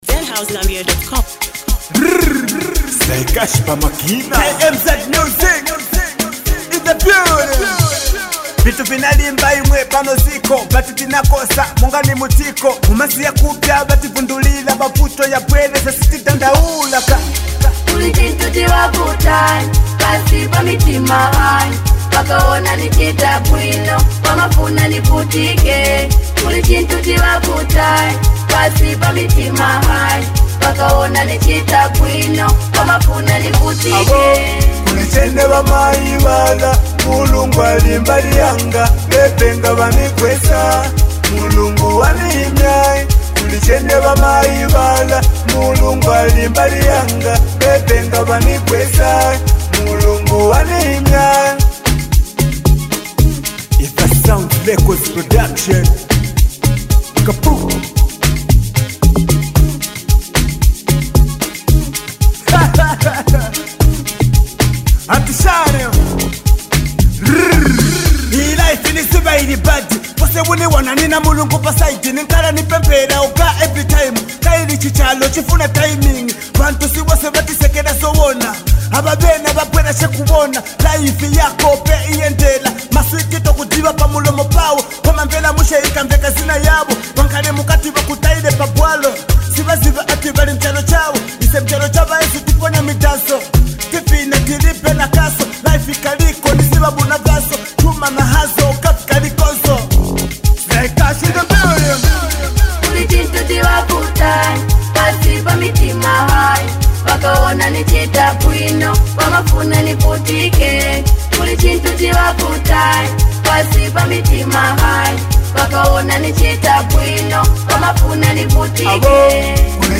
With emotional delivery